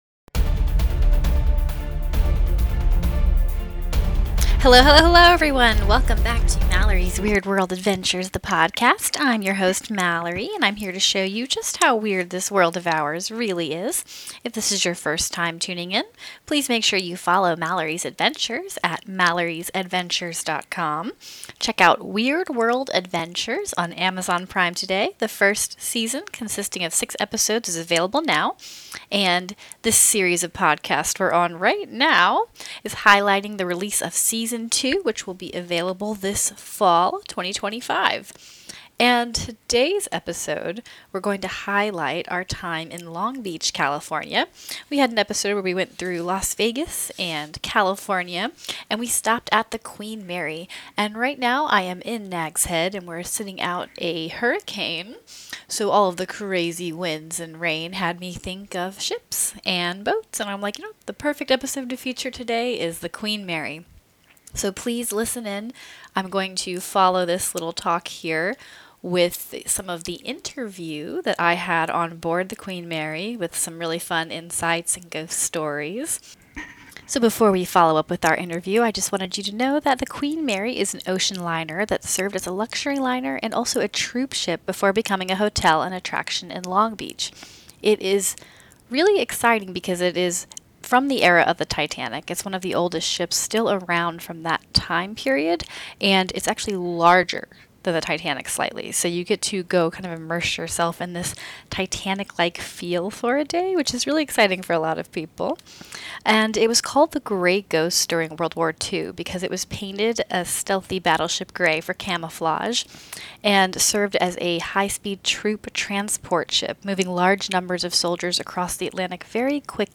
Enjoy our behind-the-scenes interview onboard the ship.